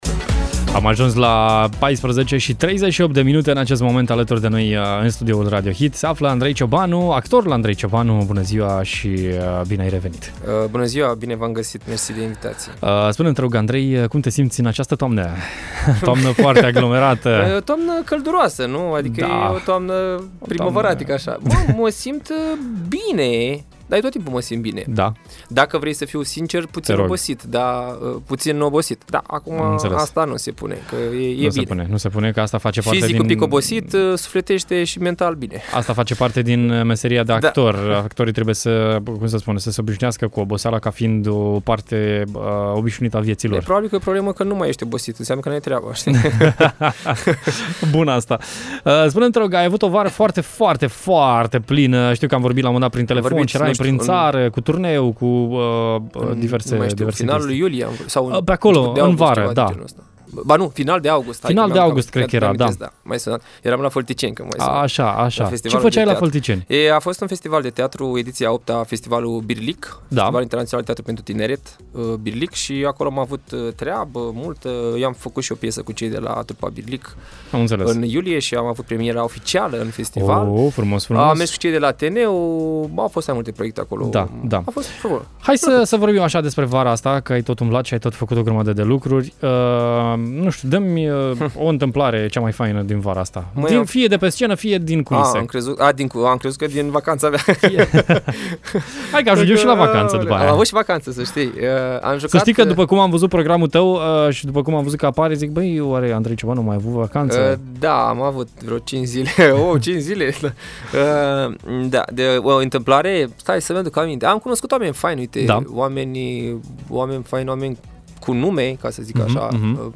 în direct la Radio Hit: